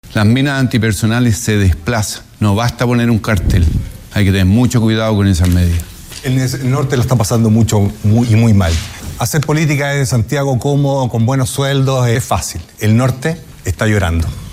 Los dardos cruzados que dejó primer debate presidencial televisivo